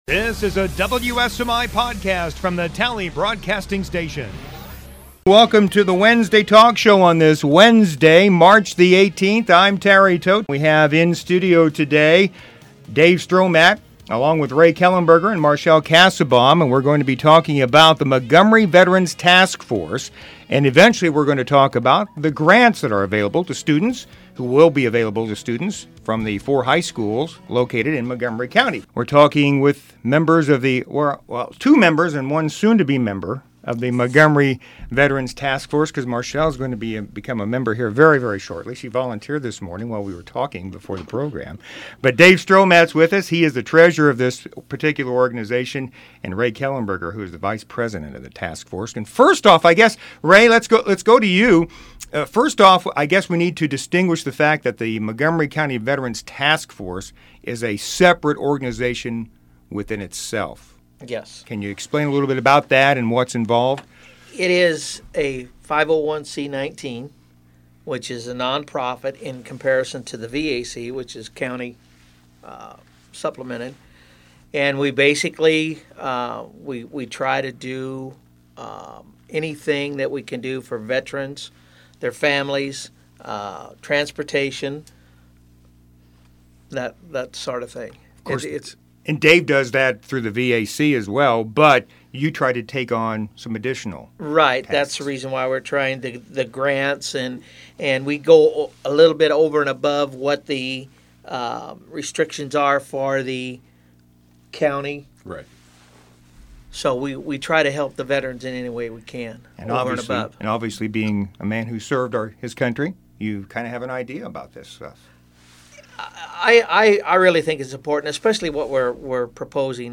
Podcasts - Wednesday Talk
03/18/2015 WSMI Wednesday Talk Show Topic: Montgomery Veterans Task Force Educational Grants .